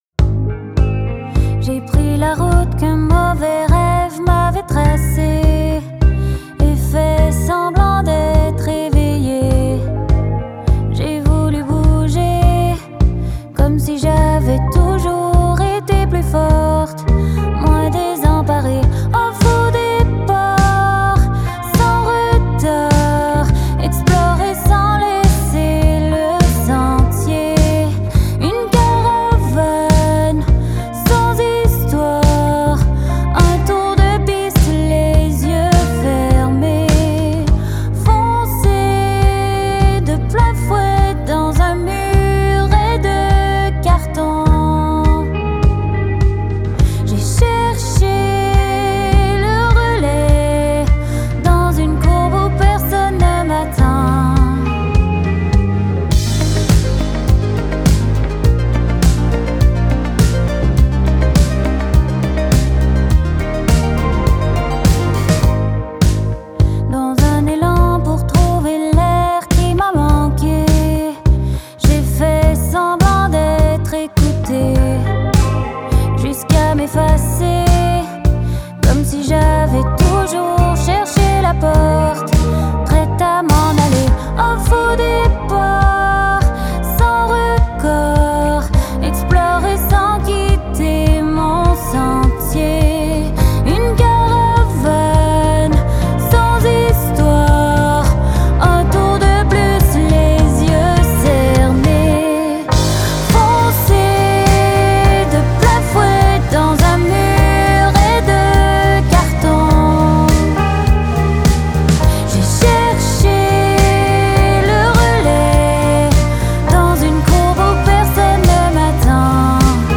soutenu par des synthés aux accents 80’s.